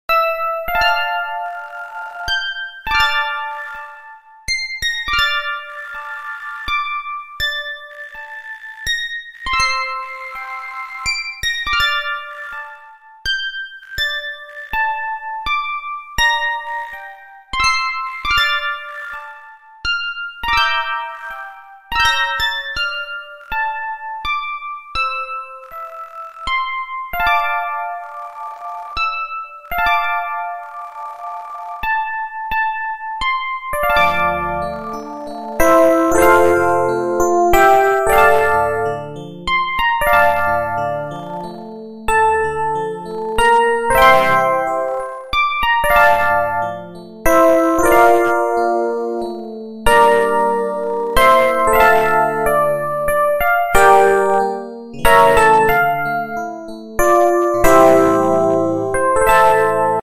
A mesmerizing blend of enchanting sound effects free download
A mesmerizing blend of enchanting music and captivating visuals 🎶✨… an ASMR experience that soothes the soul and senses